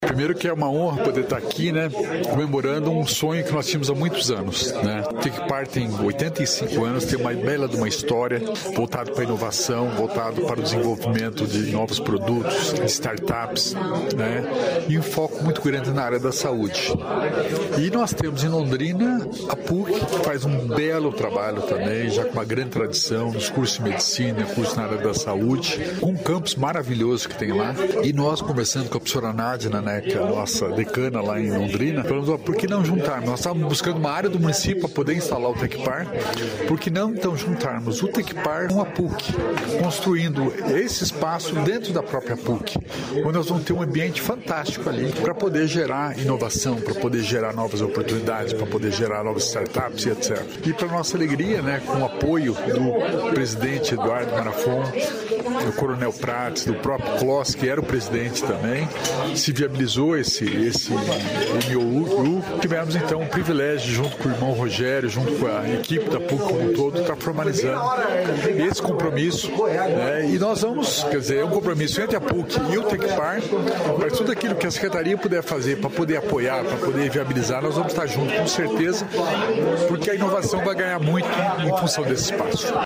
Sonora do secretário estadual da Inovação e Inteligência Artificial, Alex Canziani, sobre a parceria entre a PUC-PR e o Tecpar